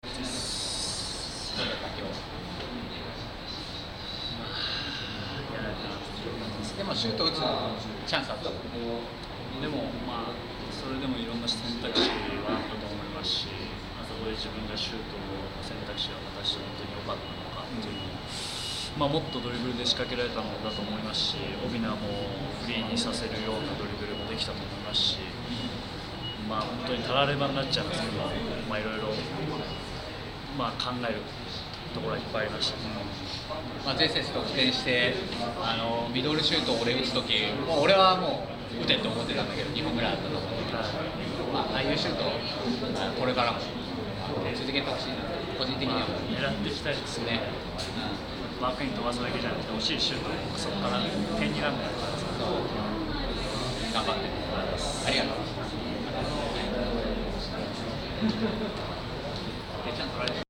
2015 J1 1st 13節 横浜Ｆ・マリノス戦 前田 直輝 インタビュー